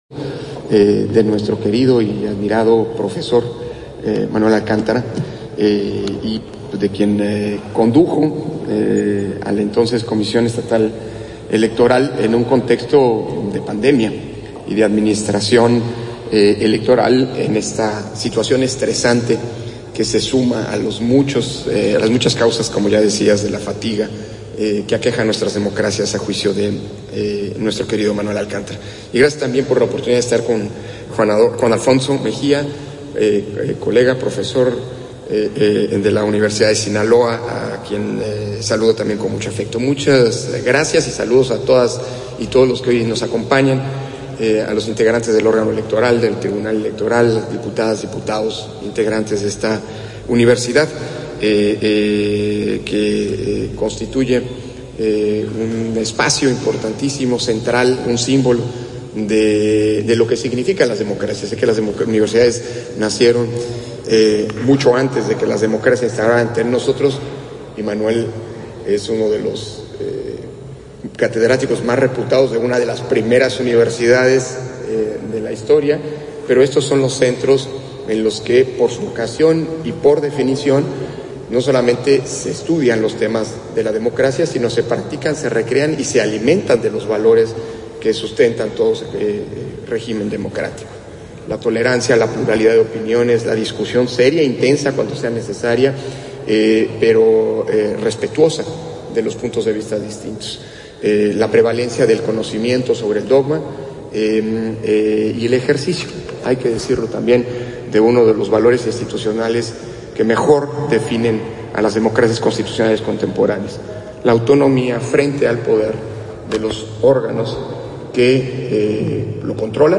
081222_AUDIO_INTERVENCIÓN-CONSEJERO-PDTE.-CÓRDOVA-PRESENTACIÓN-DEL-LIBRO-ELECCIONES-BAJO-EL-COVID-19-EN-AMÉRICA-LATINA - Central Electoral